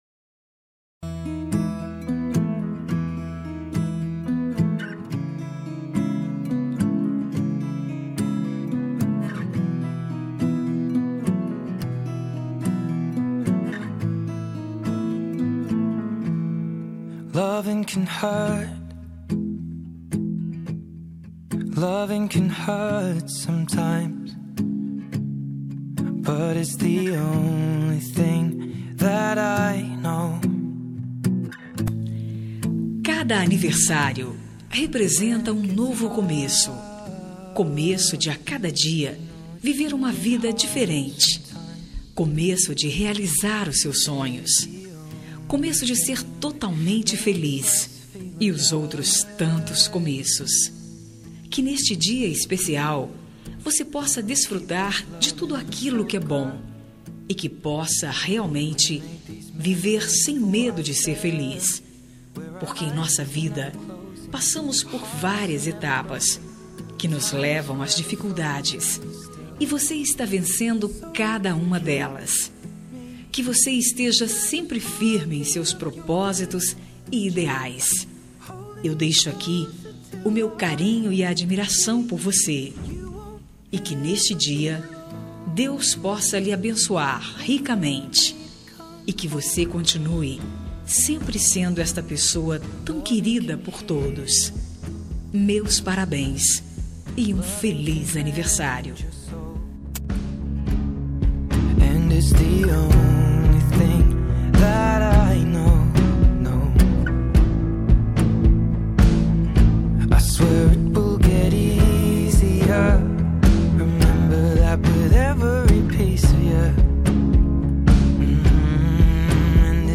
Aniversário de Pessoa Especial – Voz Feminina – Cód: 6412